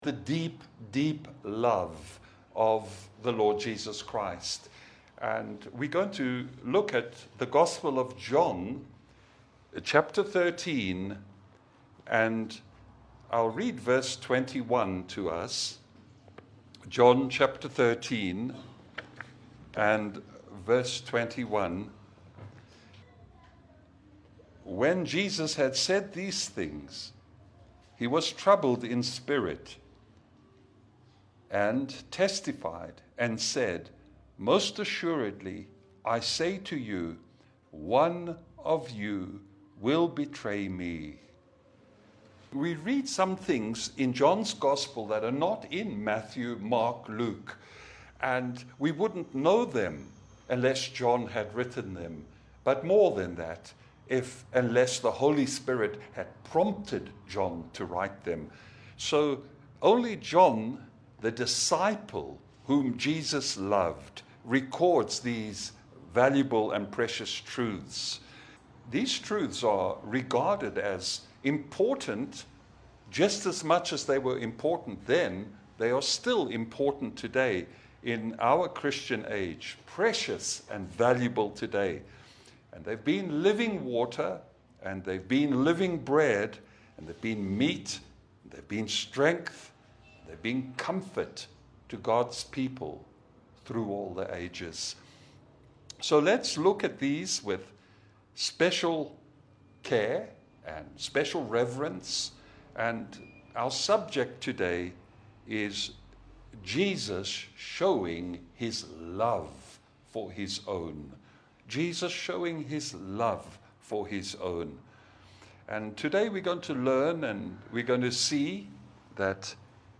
Kirimara Springs Hotel Passage: John 13:21 Service Type: Sunday Bible fellowship « David Choses Gods Way.